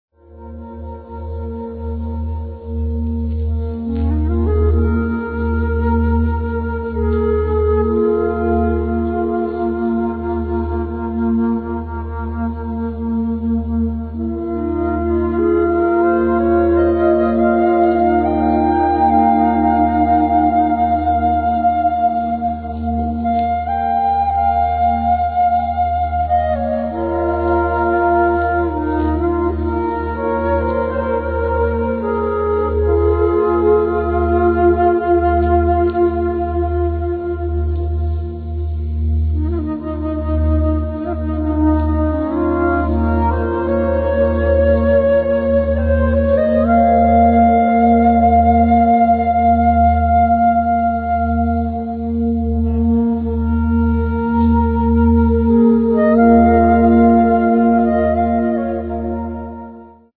Sehr anregende Chakra Musik mit starker Wirkung.